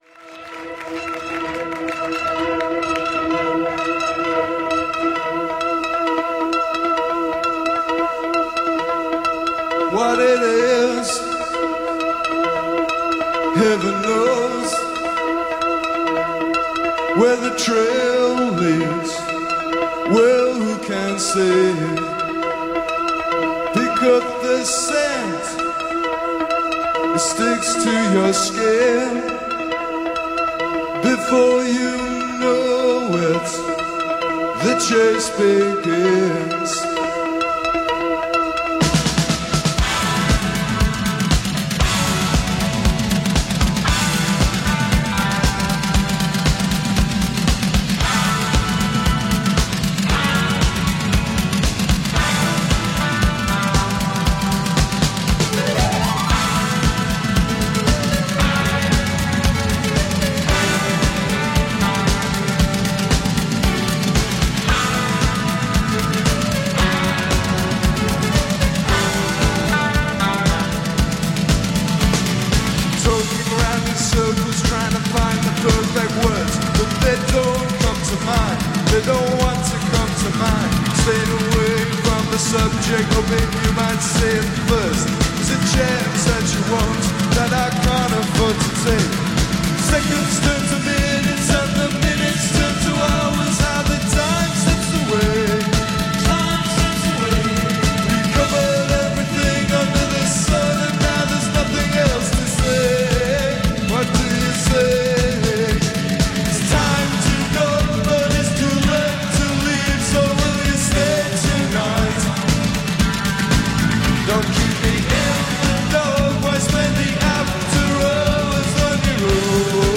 post-punk band
vocals, guitar